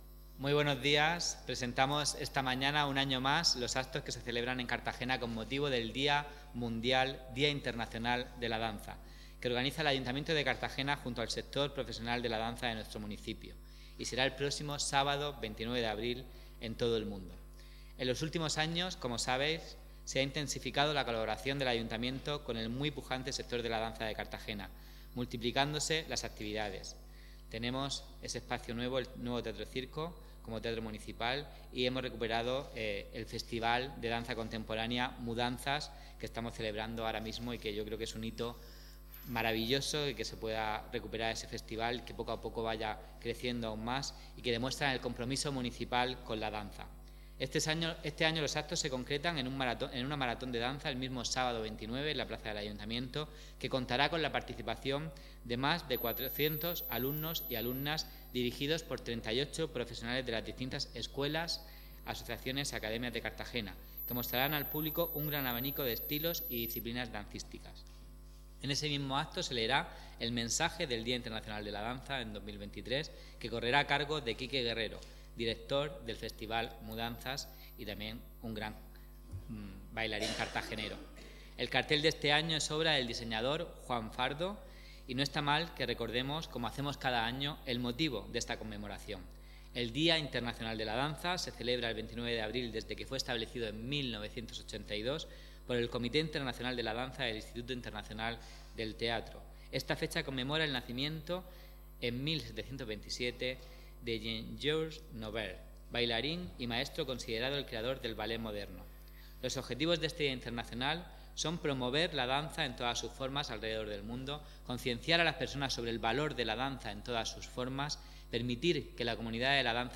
Los actos por el Día Internacional de la Danza han sido presentados este viernes, 21 de abril, por el concejal del área de Cultura, David Martínez Noguera